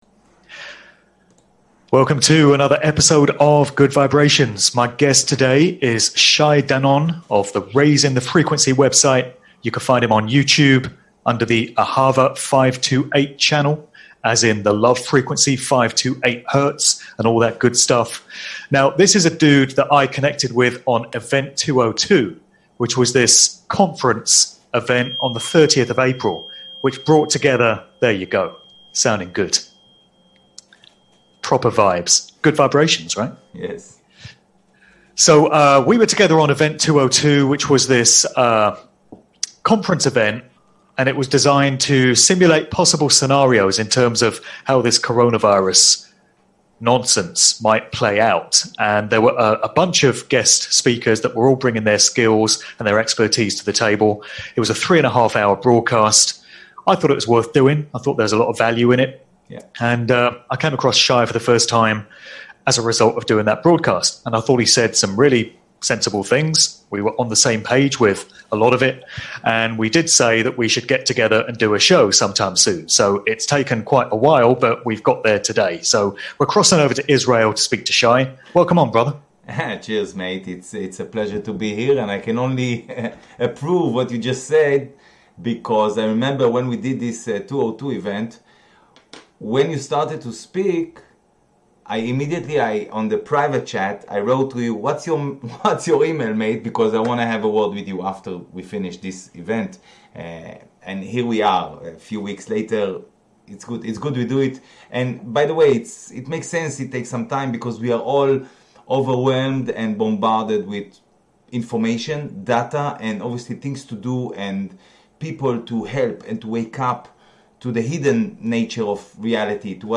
Experts Interviews